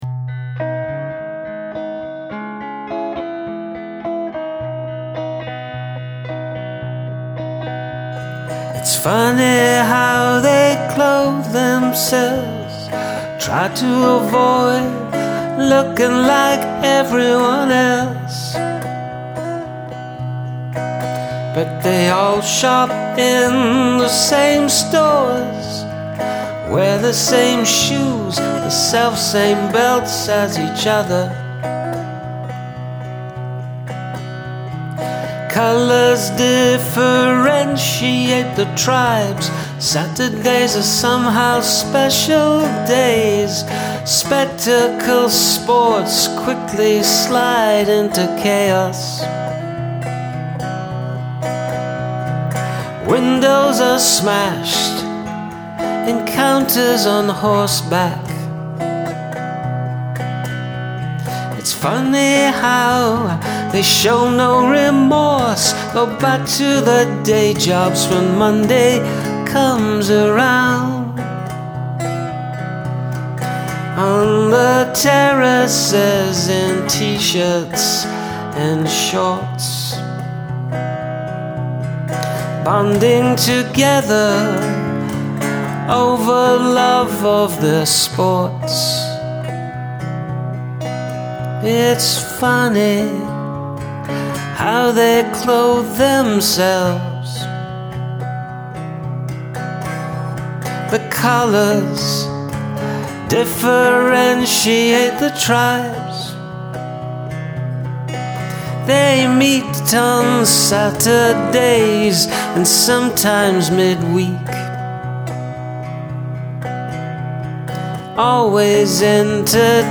Love your relaxed approach in this song.
This reminds me of sixties songs.